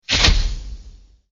Classic-old-camera-flash-poof-sound-effect.mp3